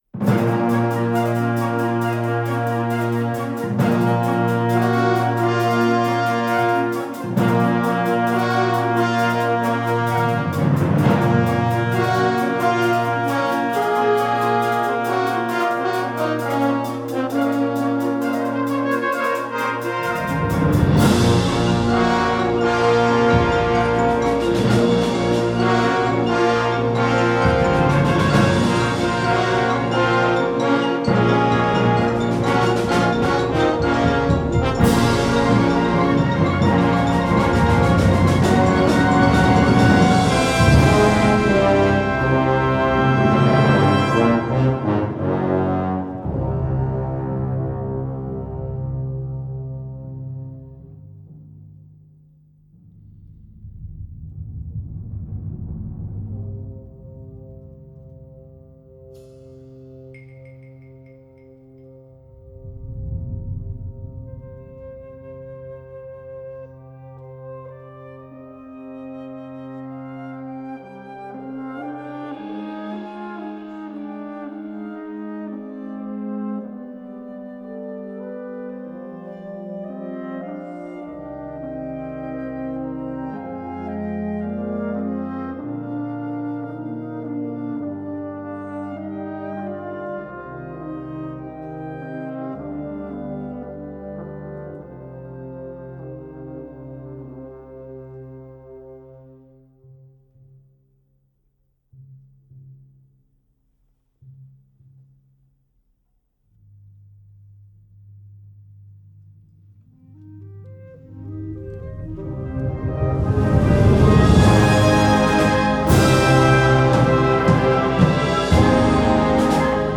Rhapsody für Blasorchester
Besetzung: Blasorchester